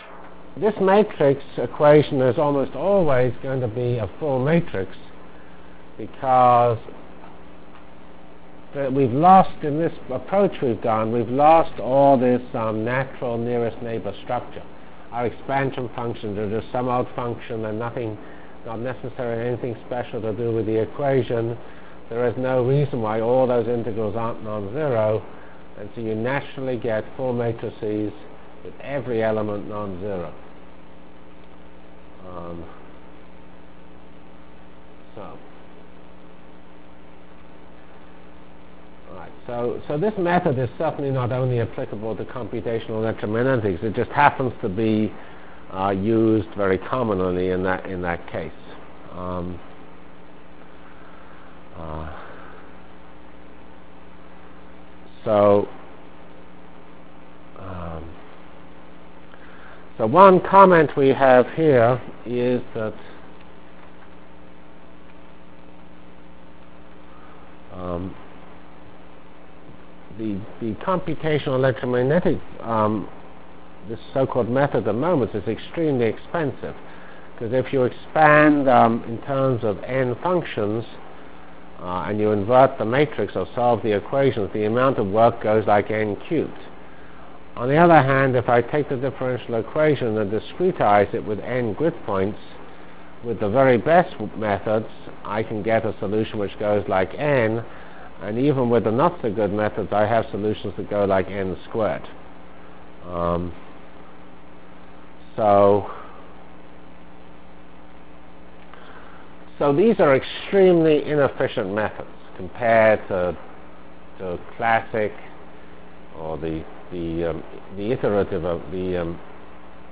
Delivered Lectures of CPS615